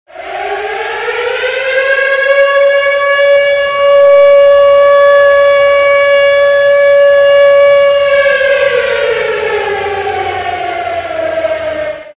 Звук воздушной тревоги… Объявление о начале войны.